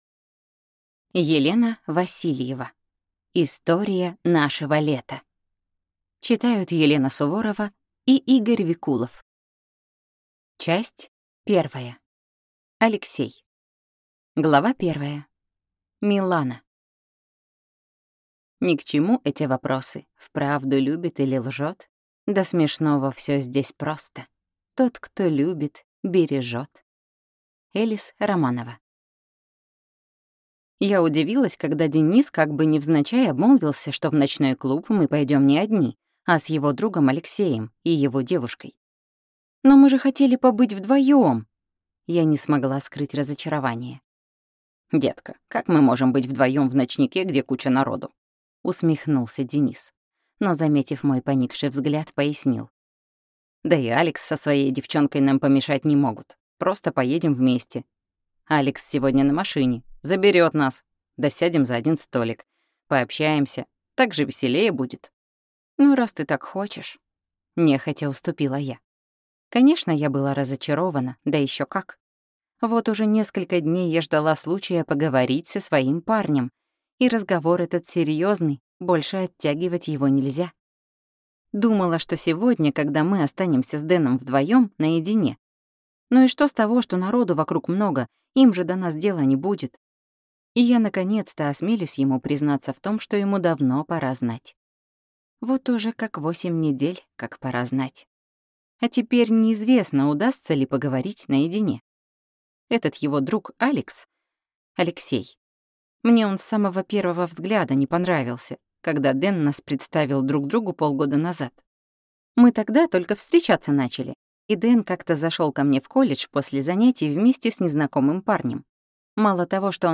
Аудиокнига Тюльпаны для Лили | Библиотека аудиокниг